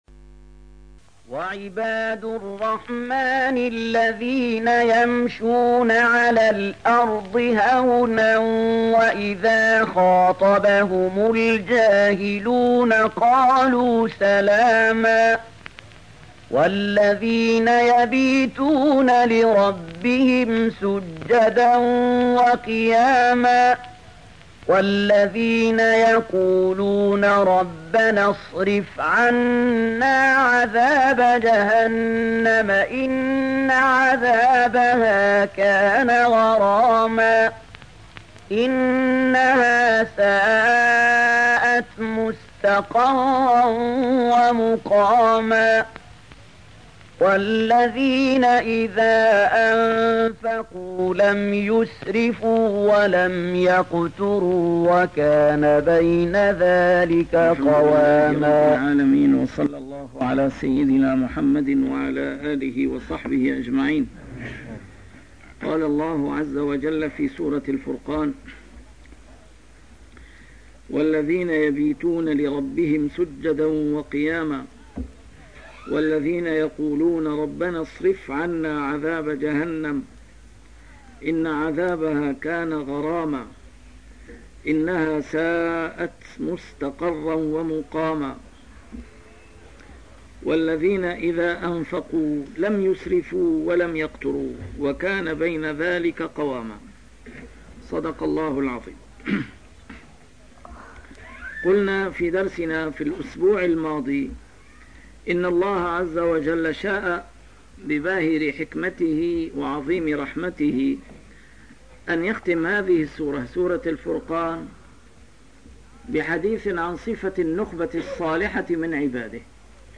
A MARTYR SCHOLAR: IMAM MUHAMMAD SAEED RAMADAN AL-BOUTI - الدروس العلمية - تفسير القرآن الكريم - تسجيل قديم - الدرس 219: الفرقان 64-67